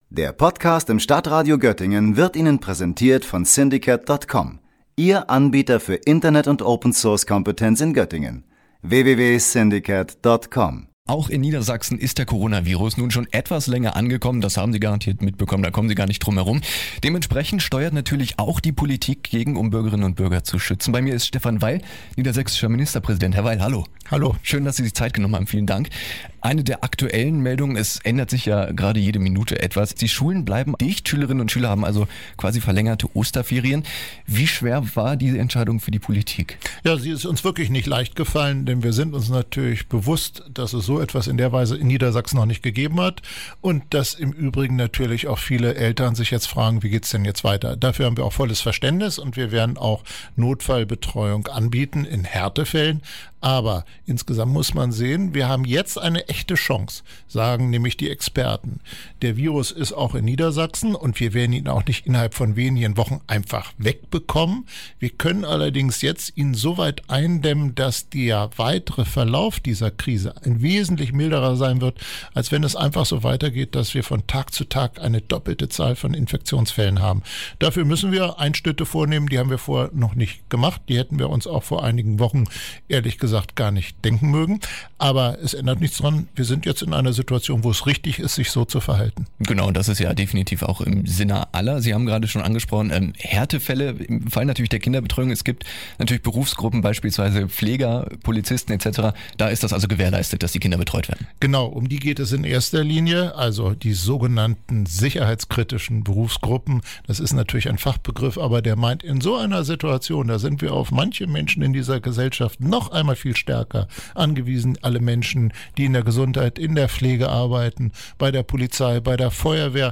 Ministerpräsident Stephan Weil im Studio von radio aktiv in Hameln (Bild: radio aktiv)